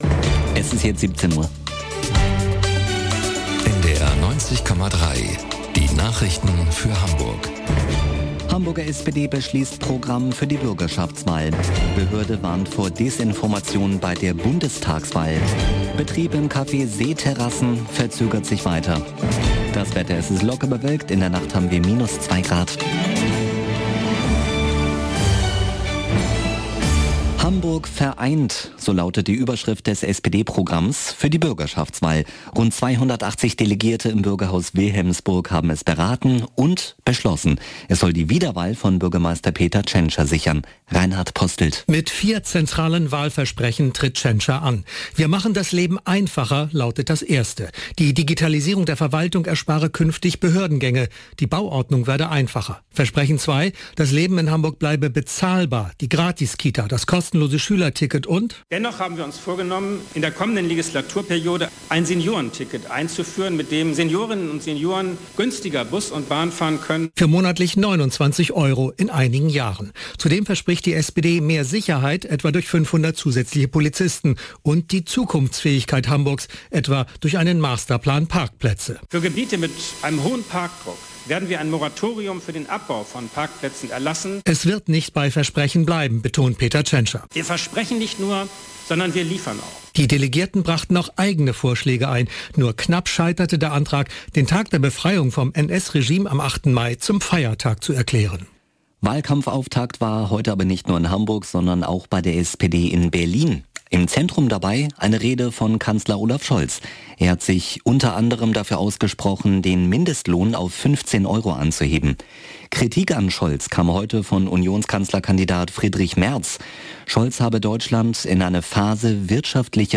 1 Nachrichten 4:50